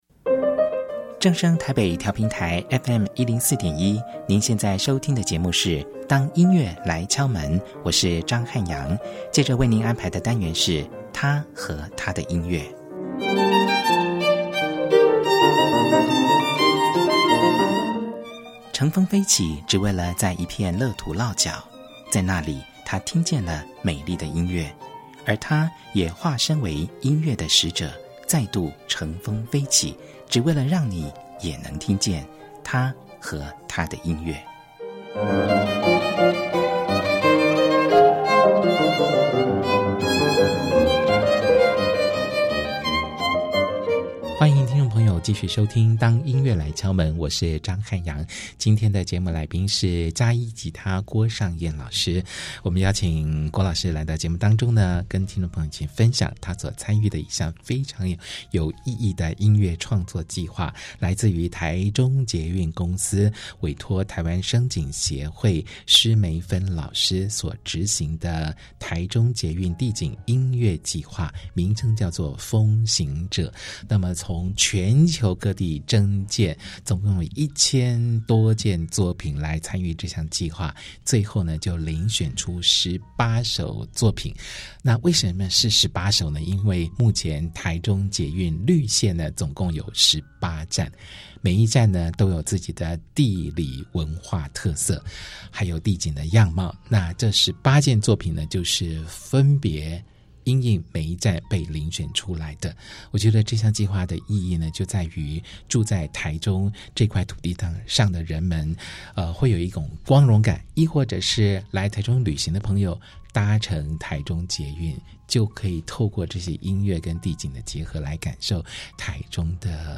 包括兩把古典吉他、小提琴、大提琴、長笛
並選播五首原曲作品及其改編版本。